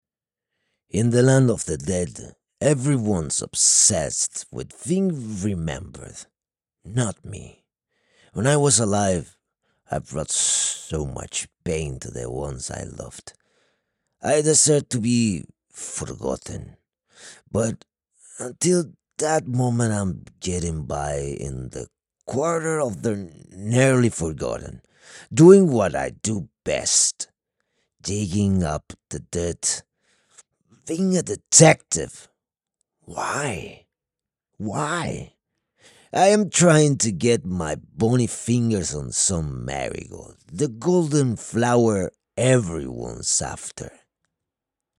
Espagnol (argentin)
Livres audio